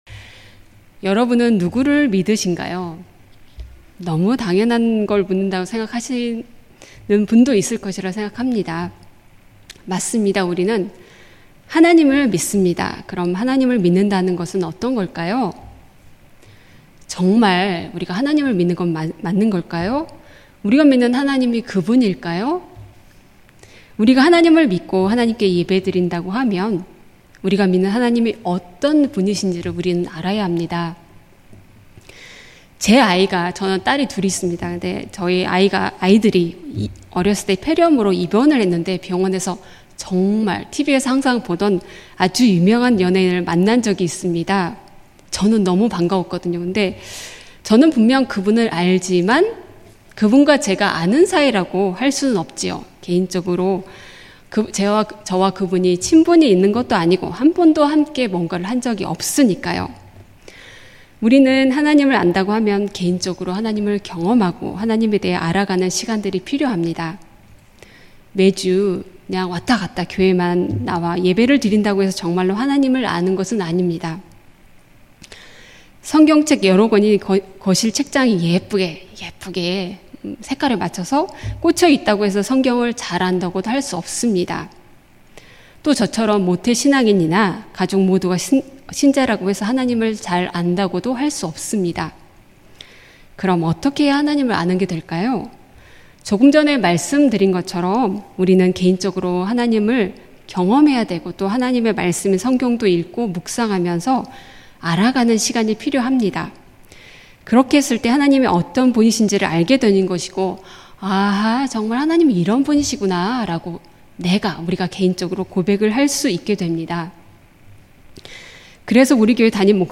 2024년 8월 4일 주일오후예배 (아기부서 성경학교 보고예배)
음성설교